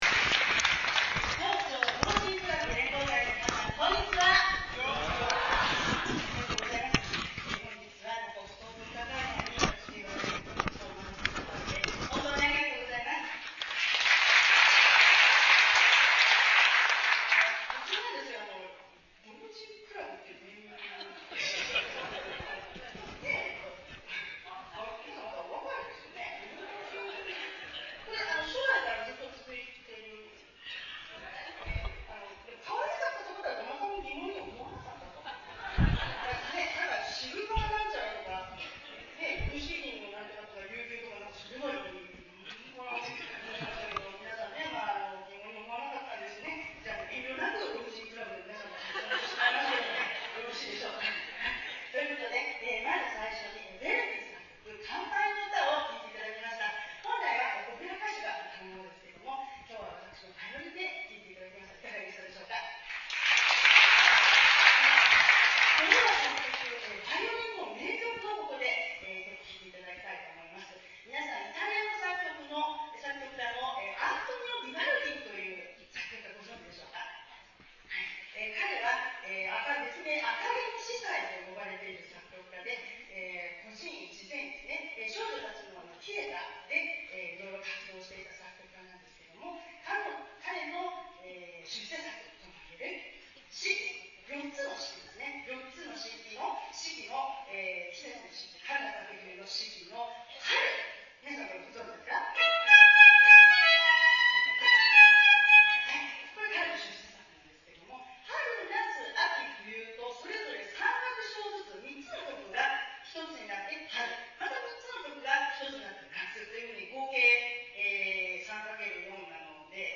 それで家を出なかったが午後には老人会主催の講演会とコンサートがあるので夫婦で出かけた。
最初に気づくのは説明を読むと楽器はガタノ ポラストリで万延2(1851)年のボローニャ製と記してある。
バイオリン.mp3